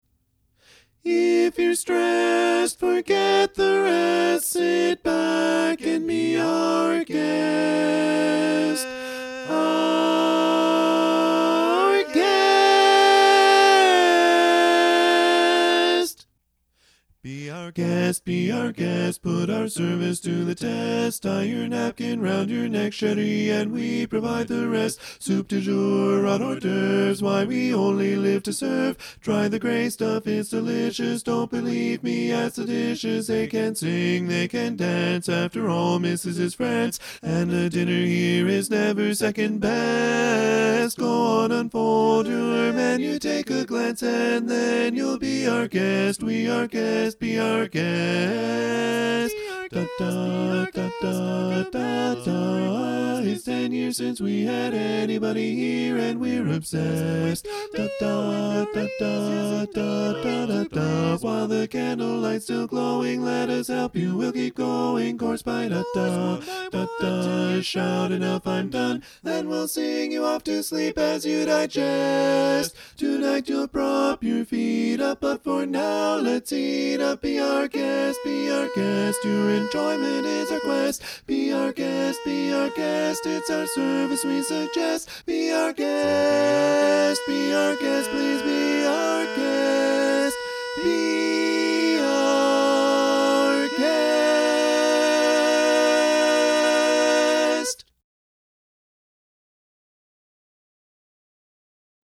Up-tempo
B♭ Major
Bari